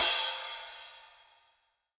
soft-hitfinish.ogg